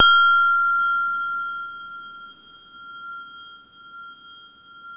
WHINE  F4 -L.wav